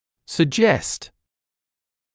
[sə’ʤest][сэ’джэст]предлагать, выдвигать в качестве предположения